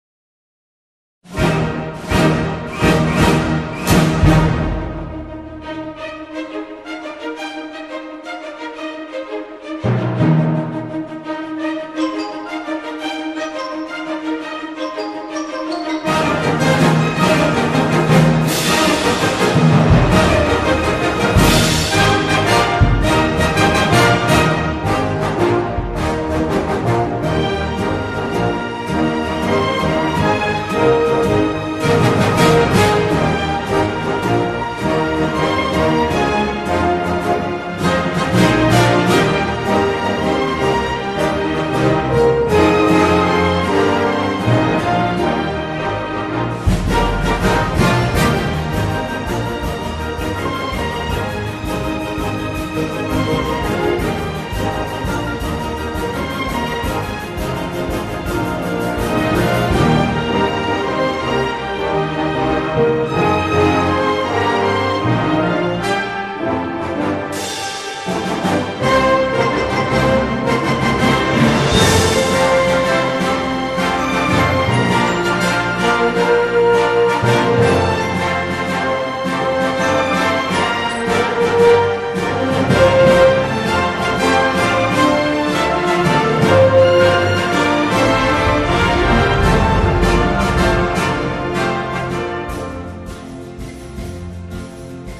la espléndida melodía de regusto tabaquero